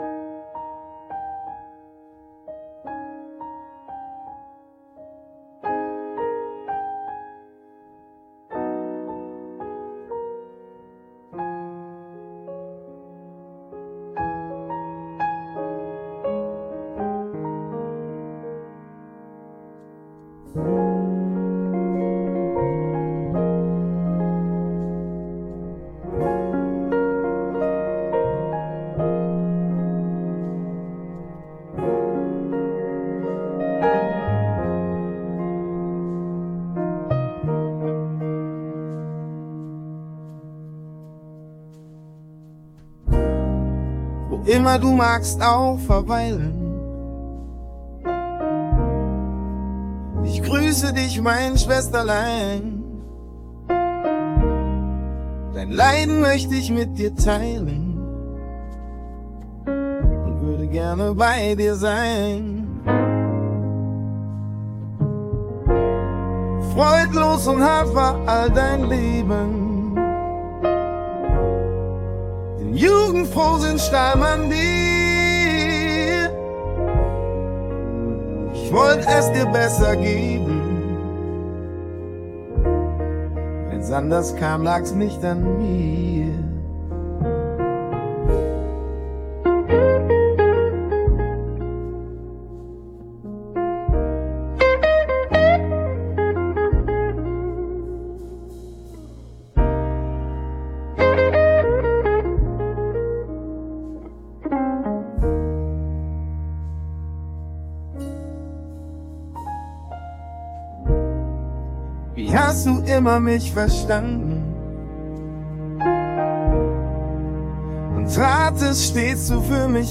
performed by Iris Berben
[Udio / Prompting: Q Kreativgesellschaft, Wiesbaden]
Ein-Gruss-Song.m4a